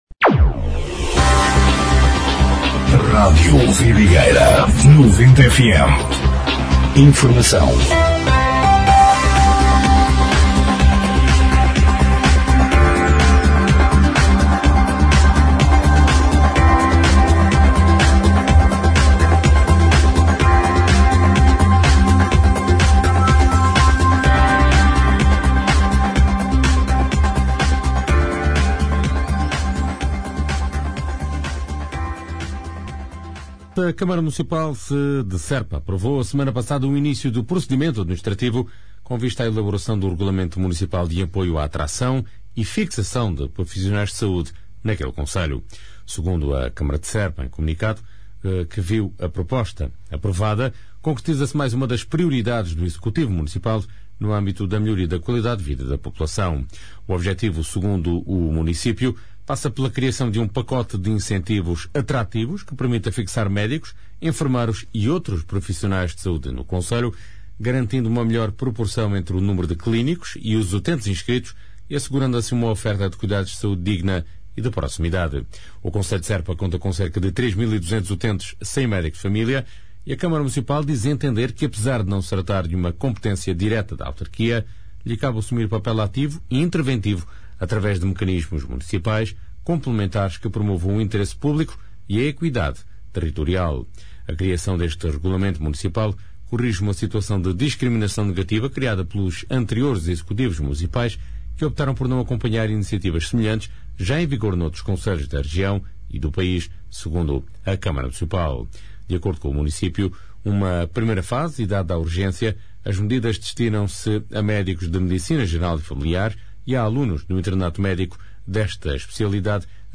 Noticiário 13/04/2026